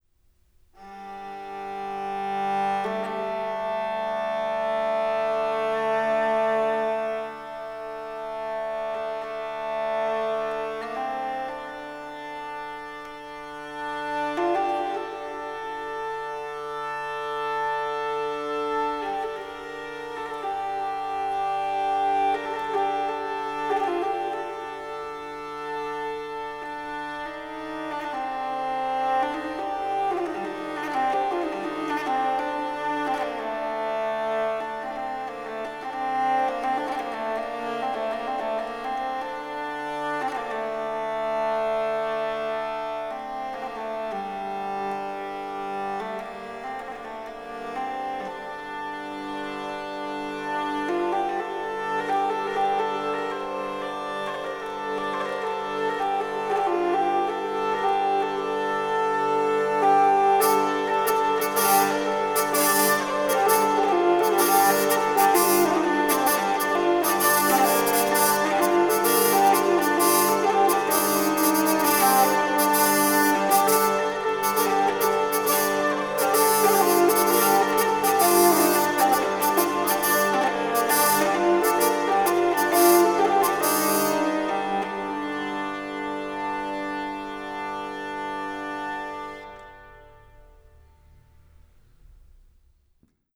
Drehleier
Die Drehleier ist ein mechanisiertes Streichinstrument, bei der ein oder mehrere Saiten über ein von einer Kurbel angetriebenes Rad verlaufen, das die Funktion des Bogens übernimmt und einen ununterbrochen durchgehenden Ton ermöglicht. Zur Melodiesaite, die über eine Tastatur wie ein Monochord abgegriffen werden kann, treten ein oder mehrere Bordunsaiten hinzu, die – wie bei den Bordunpfeifen des Dudelsacks – einen unveränderlichen Begleitton erzeugen.
musikinstrumentenmuseum_drehleier_durch_barbarei_arabia_0.m4a